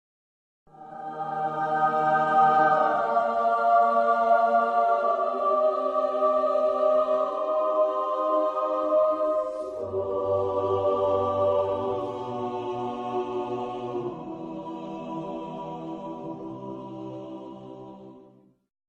Heavenly Music Sound Button - Free Download & Play